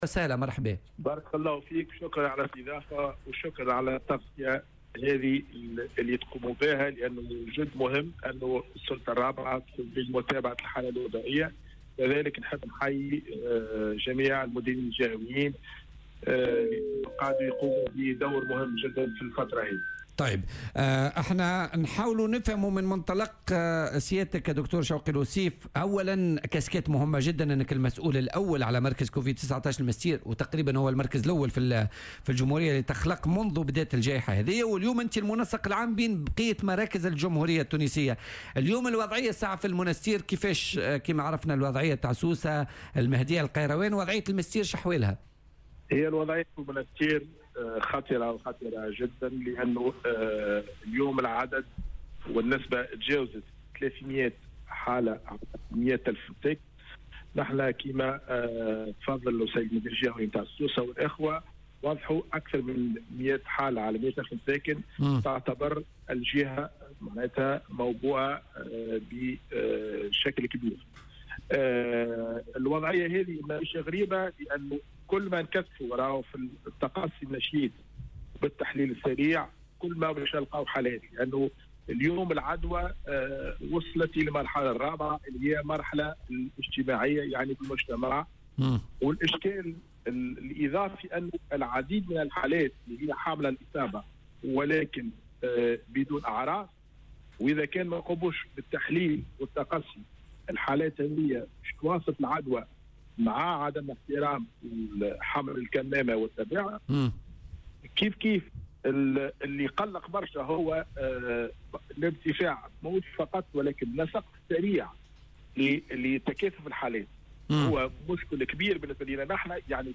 وقالوا في مداخلة لهم اليوم الخميس في برنامج "بوليتكا" على "الجوهرة أف أم" إن هناك ضغطا كبيرا على أقسام الإنعاش التي استوفت طاقة استيعابها على غرار مستشفى فرحات حشاد ومستشفى سهلول.